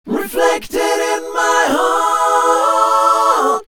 Samples of early demos, unused takes and such.